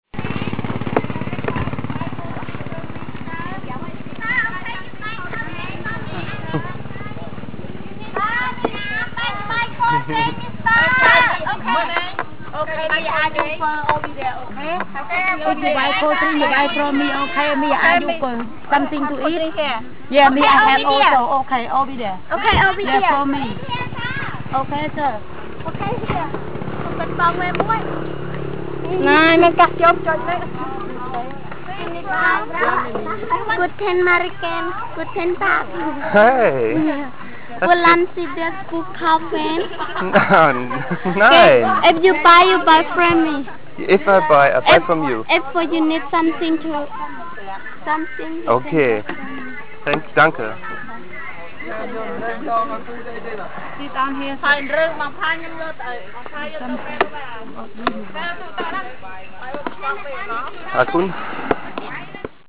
There are street restaurants infront of almost every temple.
It's really not as hustling as it sounds if you get used to it.